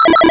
retro_synth_beeps_fast_05.wav